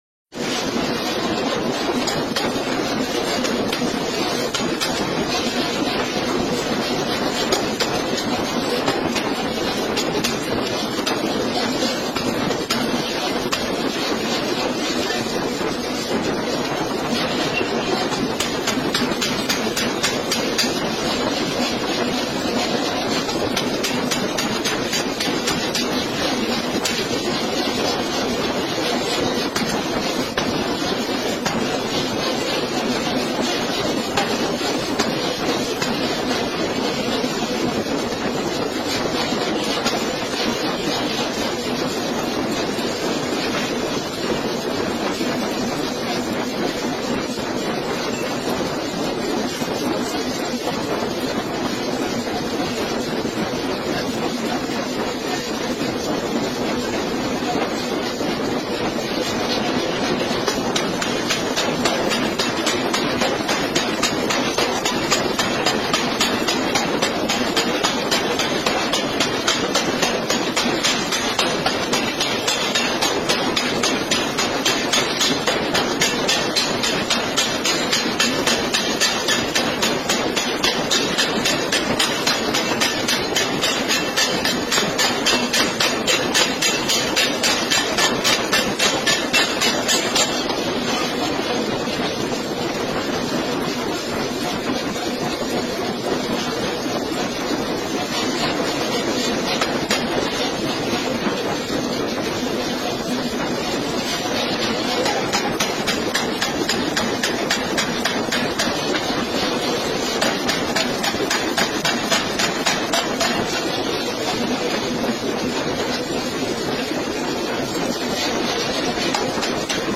Forging by Blacksmiths
There is someone running that hammer.